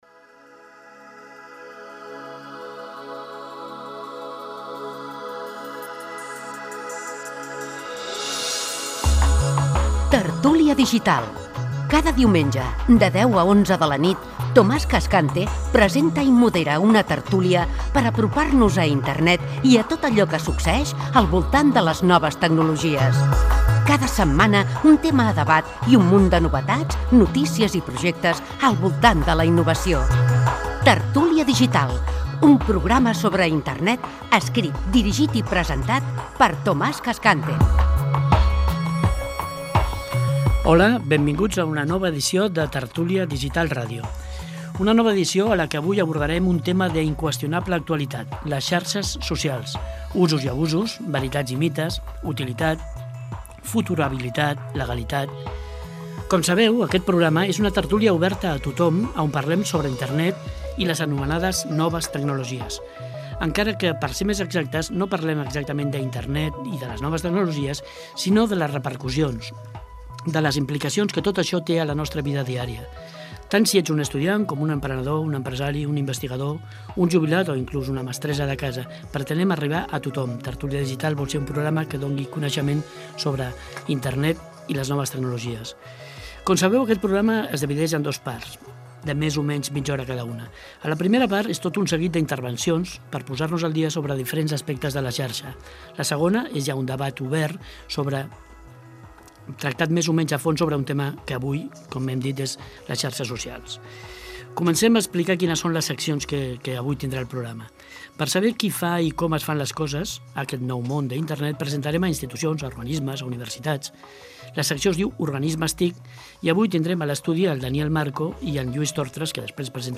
Careta del programa dedicat a Internet i les noves tecnologies. Sumari dels continguts
Divulgació